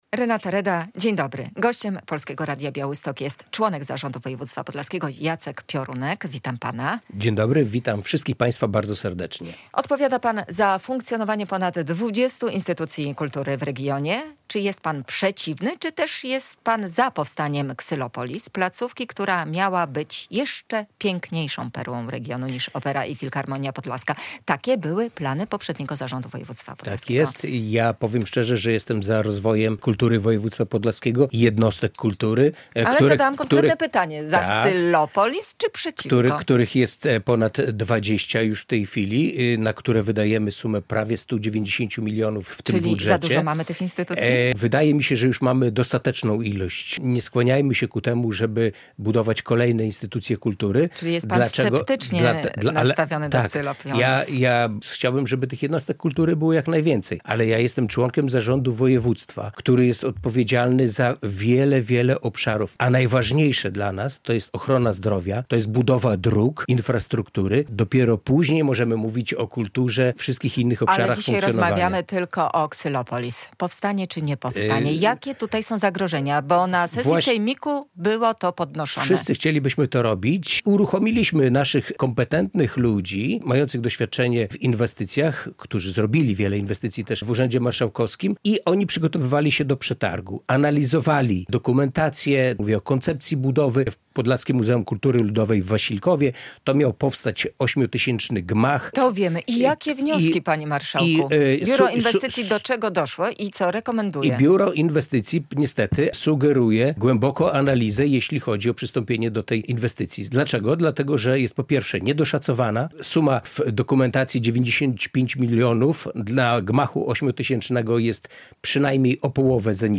Jacek Piorunek - z zarządu województwa podlaskiego